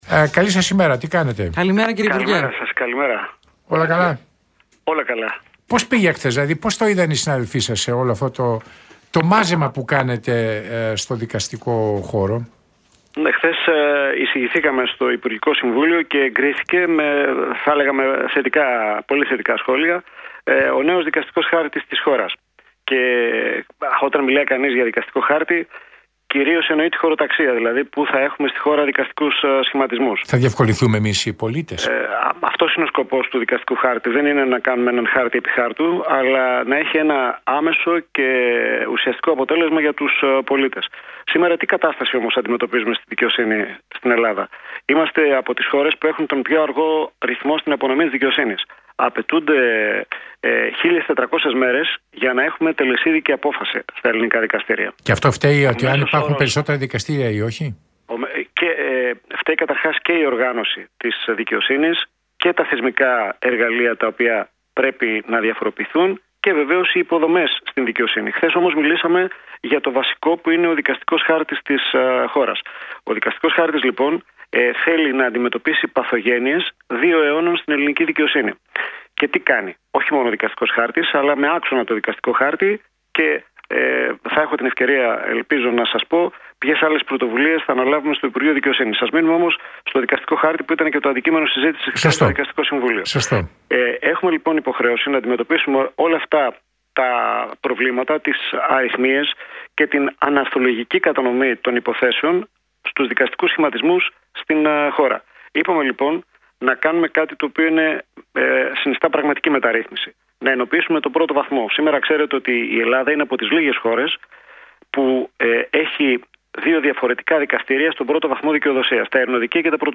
ο υφυπουργός Δικαιοσύνης, Ιωάννης Μπούγας δήλωσε στον Realfm 97,8